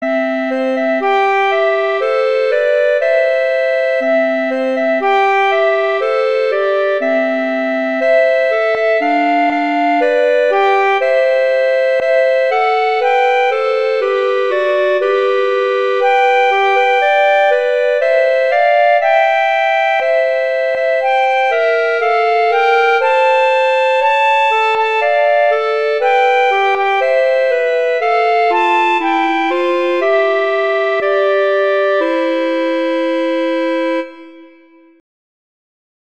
Instrumentation: two clarinets
arrangements for two clarinets